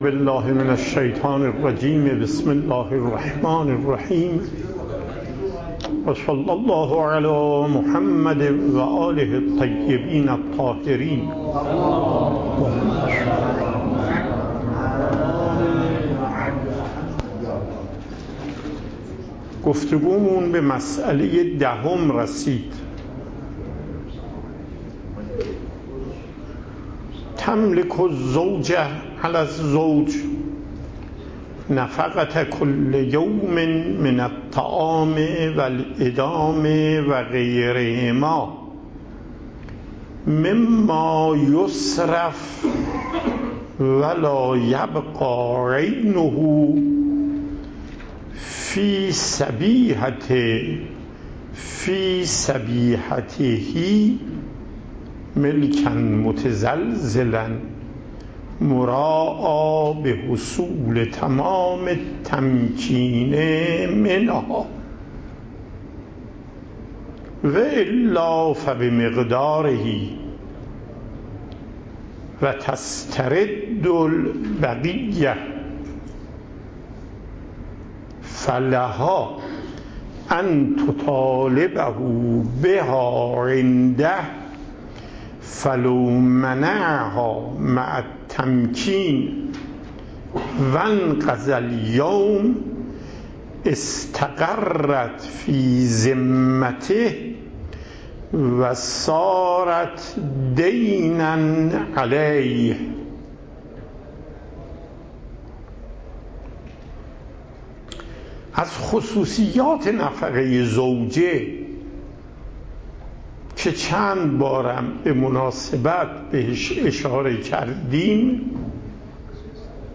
صوت درس
درس فقه آیت الله محقق داماد